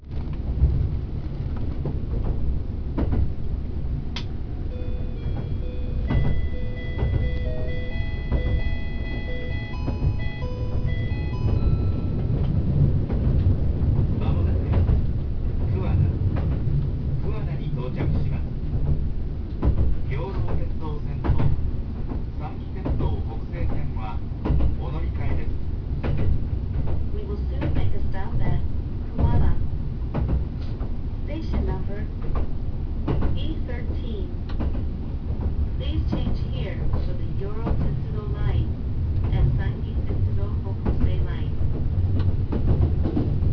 〜車両の音〜
・12410系車内放送
以前は手動放送だった気がしたのですが、いつの間にか自動放送に更新されていました。桑名到着時は汎用チャイムを用いていたものの、専用チャイムがある駅ではちゃんとそのチャイムも流れます。